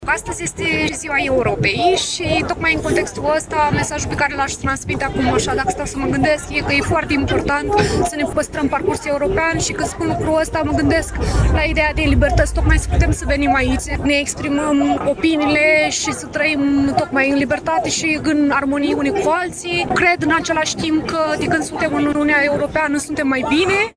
La Iași, se desfășoară un marș pro-european, la care iau parte aproximativ 1000 de persoane.
9-mai-rdj-20-vox-pop-.mp3